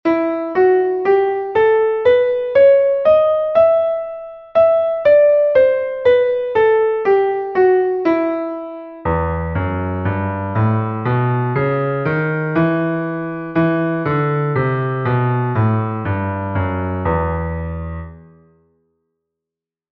E-Moll, Tonleiter aufwärts und abwärts
E-Fis-G-A-H-C-D-E